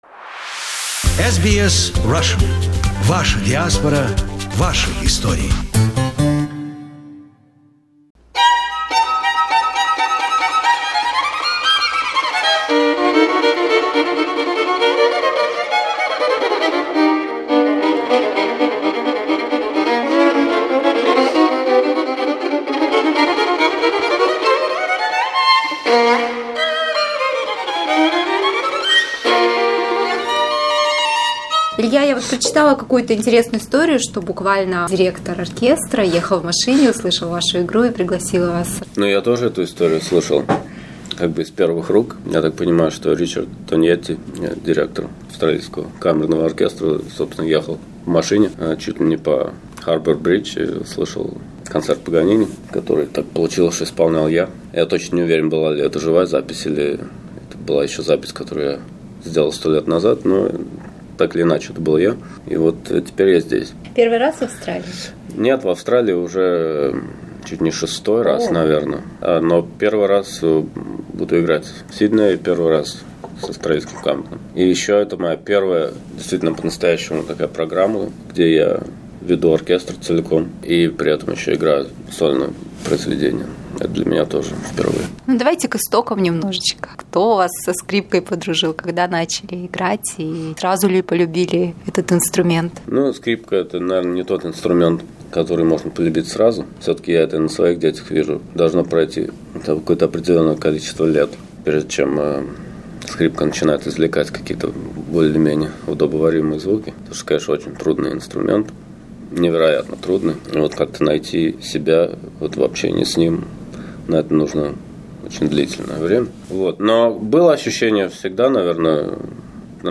From September, 30 to October, 8 a Russian violin virtuoso Ilya Gringolts will make his highly-anticipated debut with the Australian Chamber Orchestra and will perform on two extraordinary violins. We attended one of their rehearsal and had an opportunity to ask Ilya some questions about his life and his music.